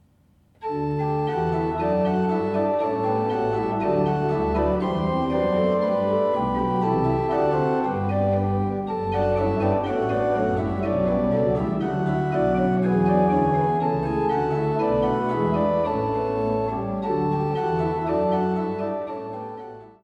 Bourdon 16'
Montre 8'
Soubasse 16'